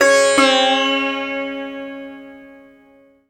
SITAR LINE13.wav